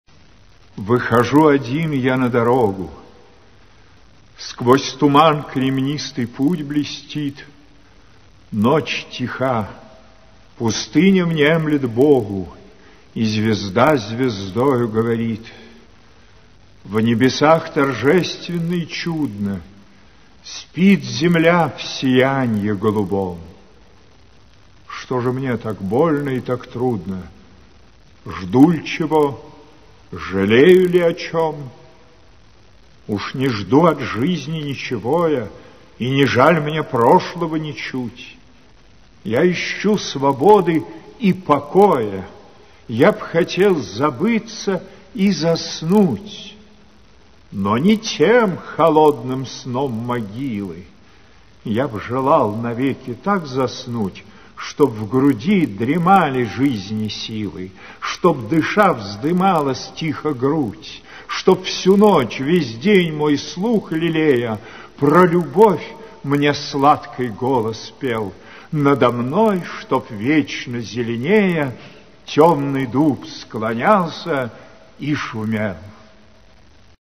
Прослушивание аудиозаписи стихотворения с сайта «Старое радио». Исполнитель М. Царев.